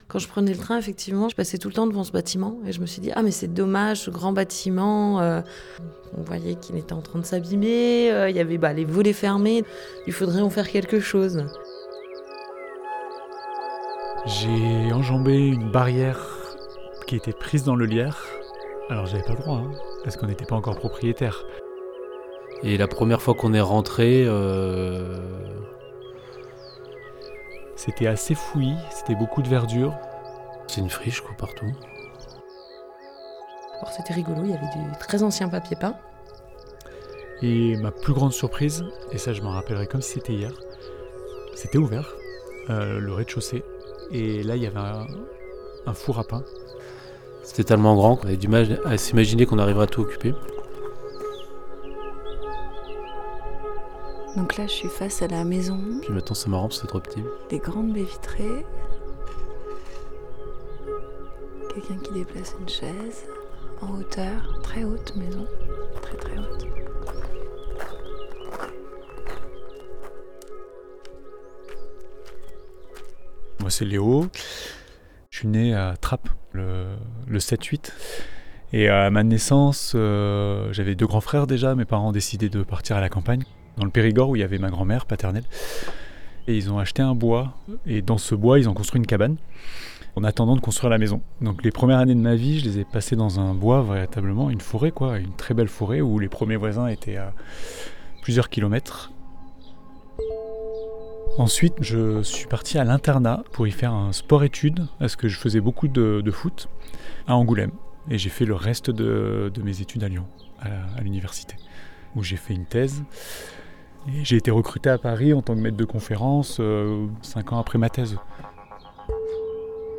A travers plusieurs témoignages, le récit qui se tisse est celui d’une aventure collective riche de son contraste, de ses hésitations, de ses joies et de ses rencontres.